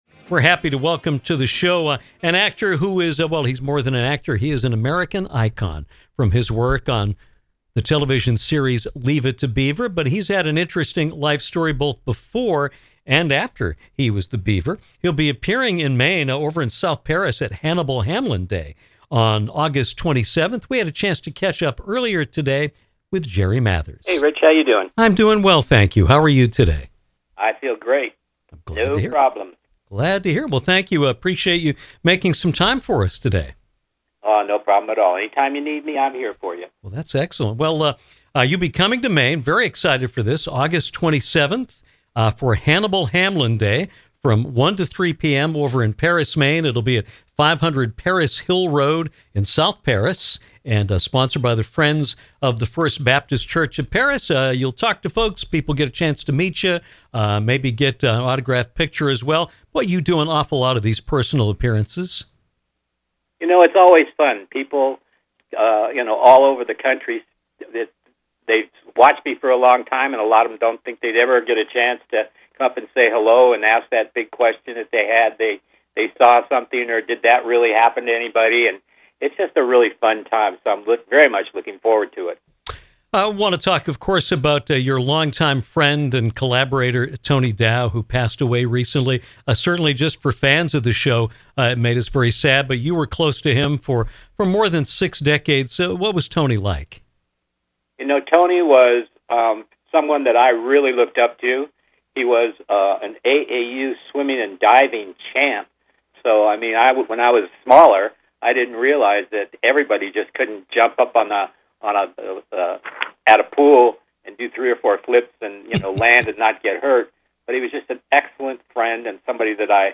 Actor Jerry Mathers joined us this afternoon to talk about working with icons Bob Hope and Alfred Hitchcock, remember his friend and “Leave It to Beaver” co-star Tony Dow, and to discuss his appearance in South Paris, Maine on August 27 as part of Hannibal Hamlin Day.
Great interview.